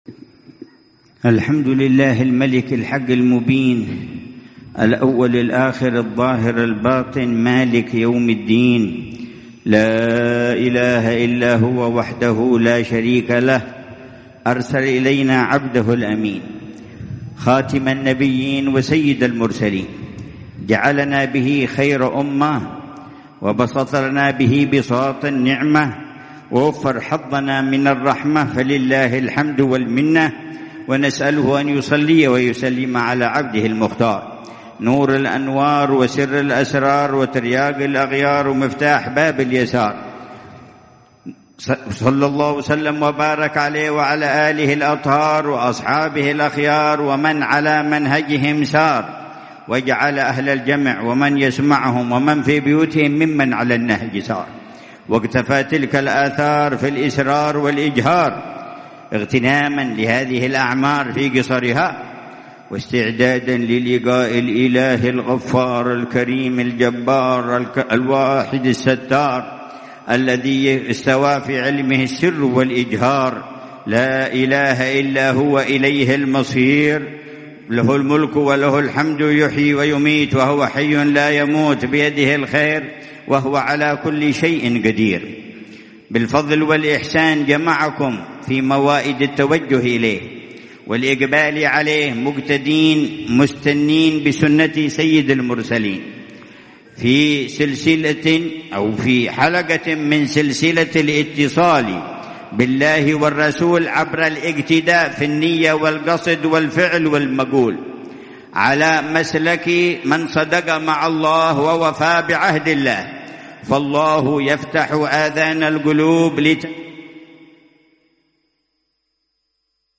محاضرة في مجلس الإحدى عشرية وذكرى سيدتنا خديجة الكبرى، القويرة، دوعن 1447هـ
محاضرة العلامة الحبيب عمر بن حفيظ، في مجلس الإحدى عشرية، وذكرى سيدتنا خديجة الكبرى، في مسجد الحبيب أحمد المحضار في منطقة القويرة بوادي دوعن، ليلة الثلاثاء 11 صفر الخير 1447هـ